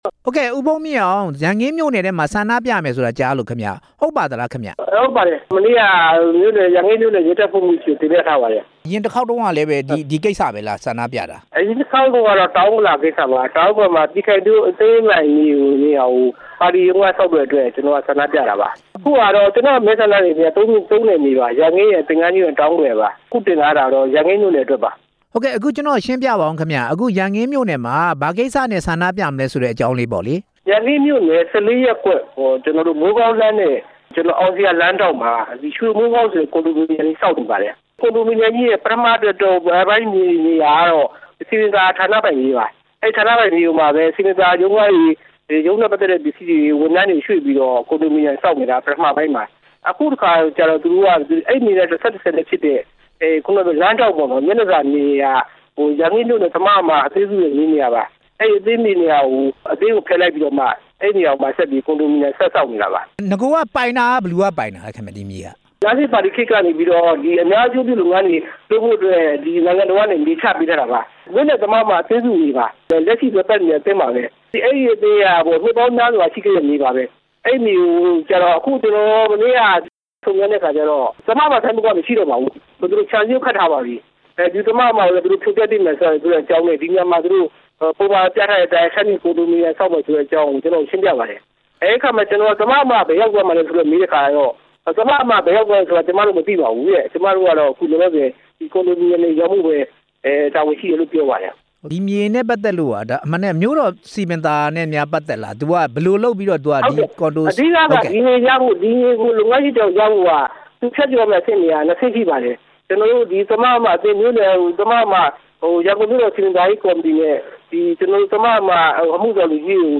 အမျိုးသားလွှတ်တော်အမတ် ဦးဖုန်းမြင့်အောင်နဲ့ ဆက်သွယ်မေးမြန်းချက်